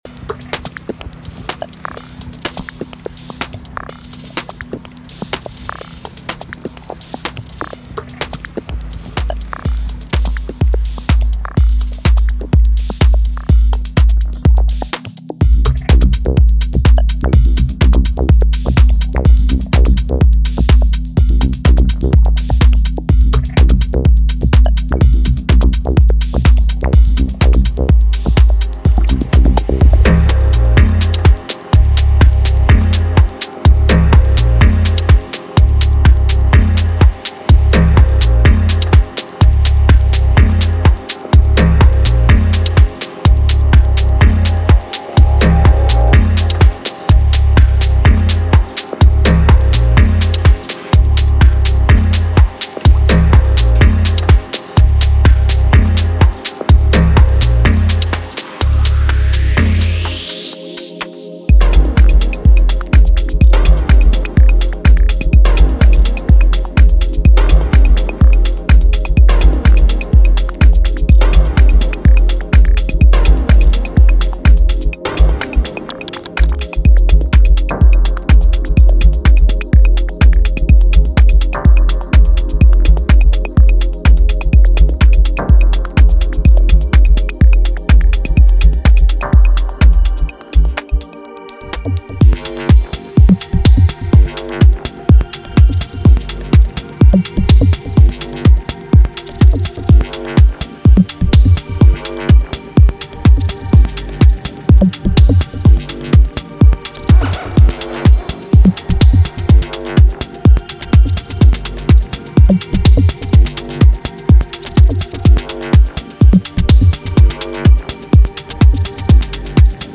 Genre:Minimal Techno
デモサウンドはコチラ↓
90 Drum loops (Full, Kick, Clap, Hihat, Perc, Top)
25 Bass loops (Key labelled)
35 Synth loops (Key labelled)
30 Pad loops (Key labelled)